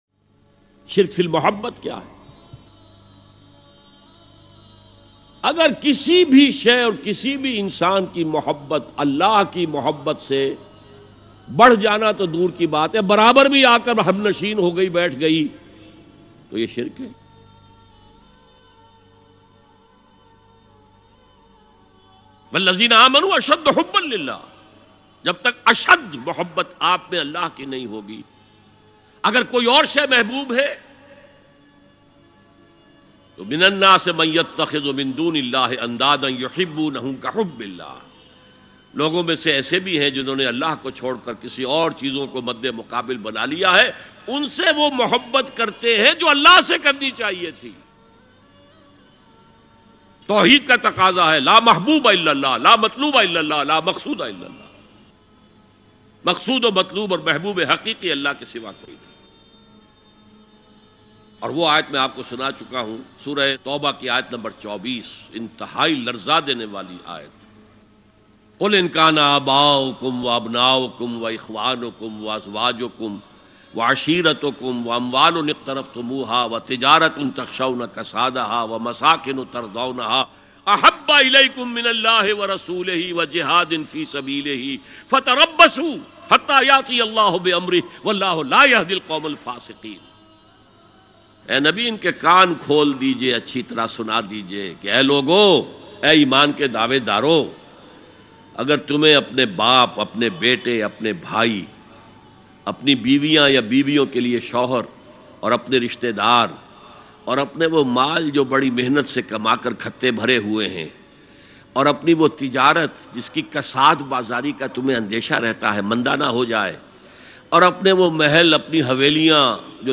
ALLAH Se Mohabbat Rula Dene Wala Bayan Shirk Fil Mohabbat Dr Israr Ahmed Very Emotional Bayan MP3 Downlaod.